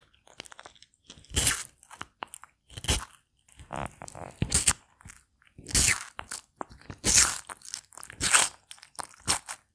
Tortoise Eating Asmr Sound Button: Unblocked Meme Soundboard